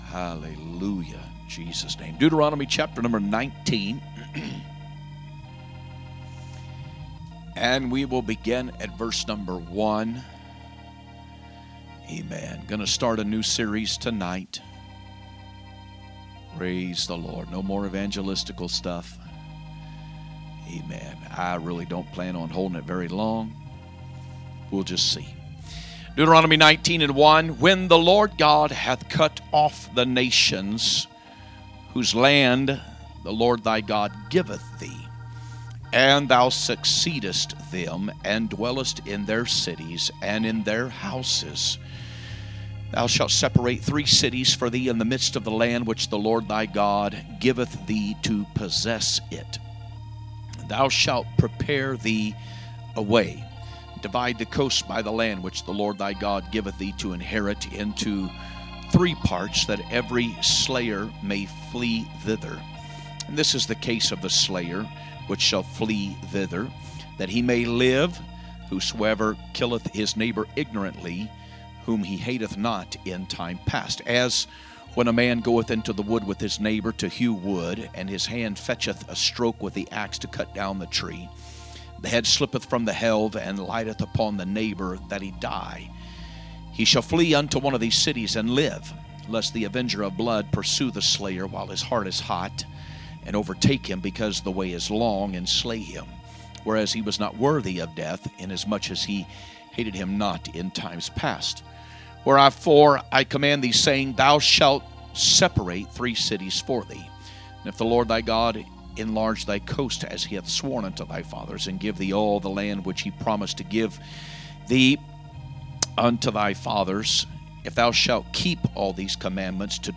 From Series: "2025 Preaching"